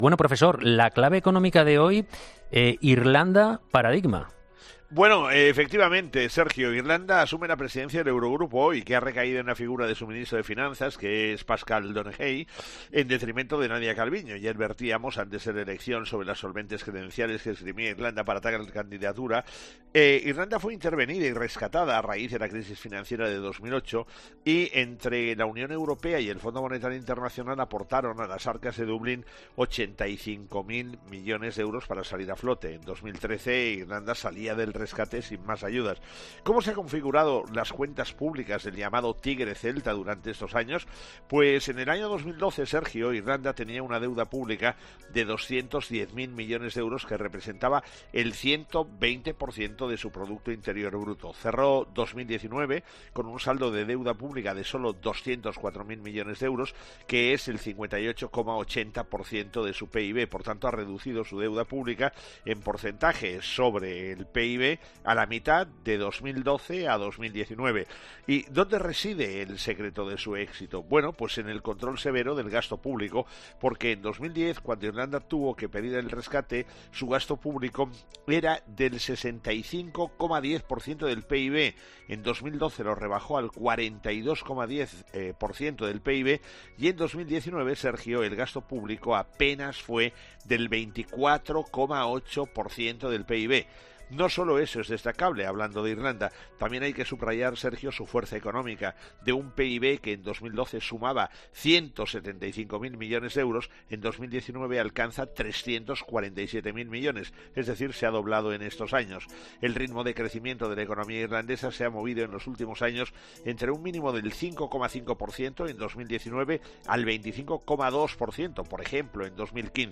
El profesor José María Gay de Liébana analiza en 'Herrera en COPE’ las claves económicas del día.